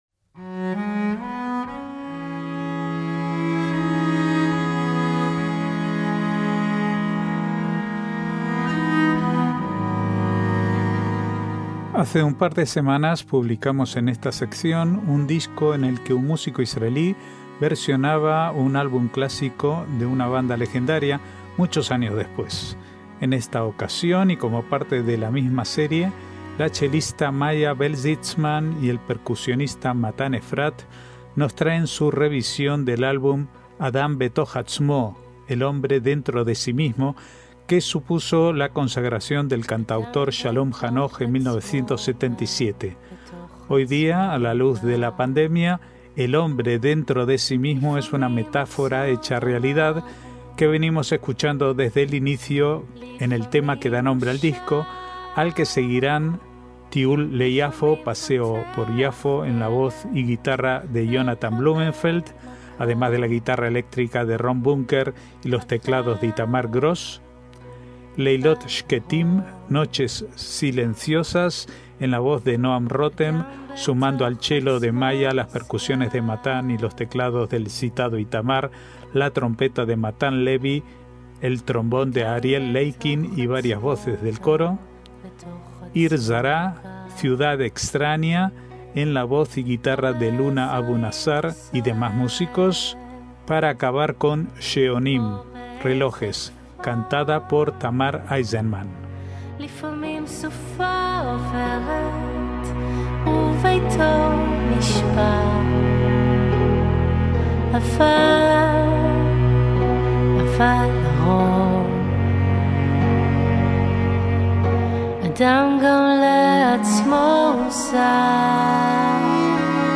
guitarra eléctrica
teclados